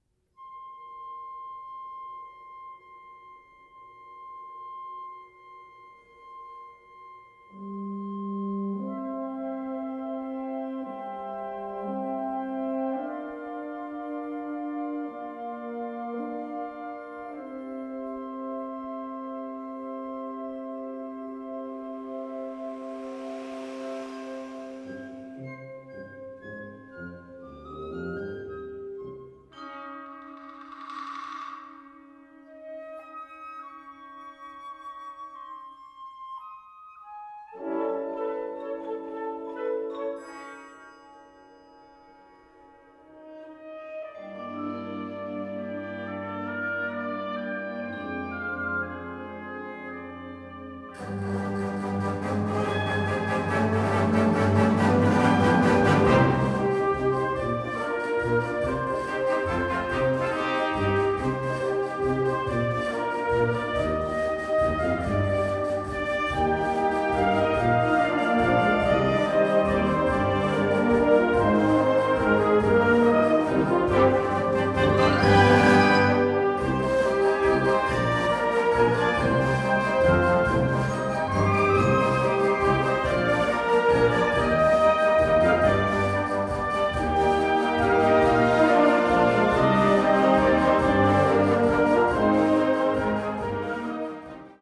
Subcategorie Concertmuziek
Bezetting Ha (harmonieorkest)